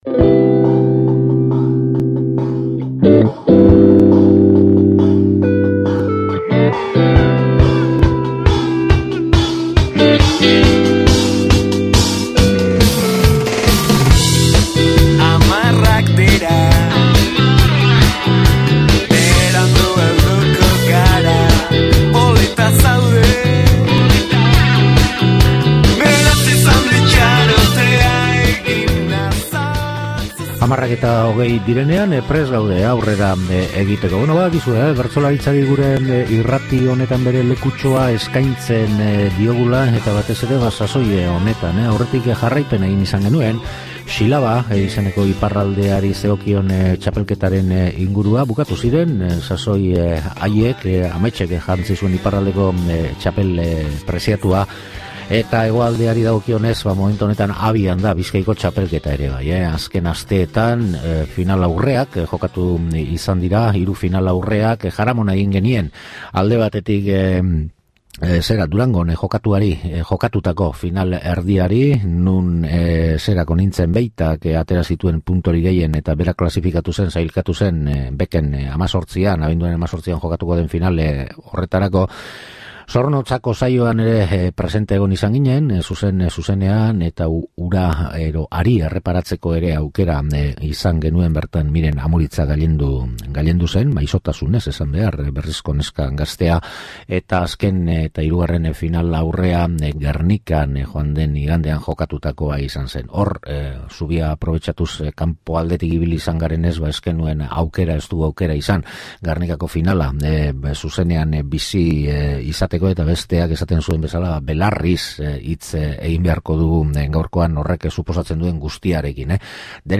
solasaldia
Bertan gertatukoaren berri eta zenbait bertsolarik Jai Alai-n kantatutakoa duzue entzungai gaurko saio honetan.